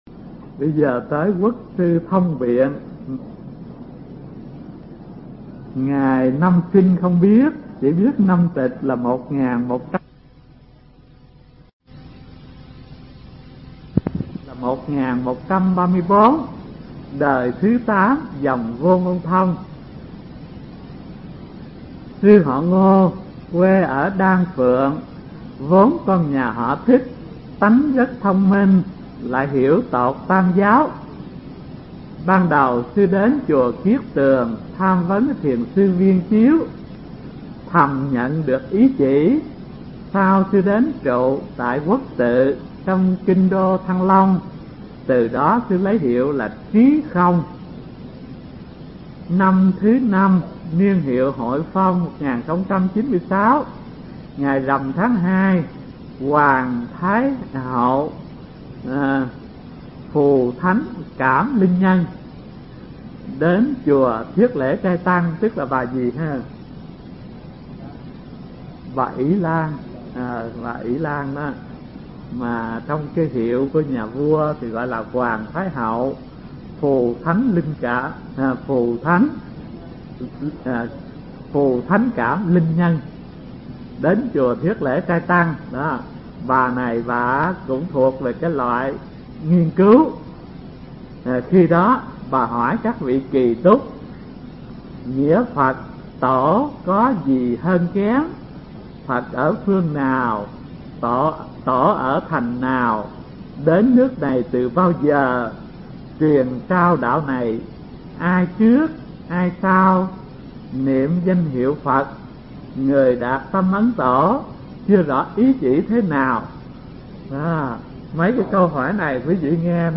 Mp3 Pháp Âm Thiền Học Phật Giáo Việt Nam 79 – Quốc Sư Thông Biện (Đời 8 Dòng Vô Ngôn Thông) – Hòa Thượng Thích Thanh Từ giảng tại trường Cao Cấp Phật Học Vạn Hạnh, từ năm 1989 đến năm 1991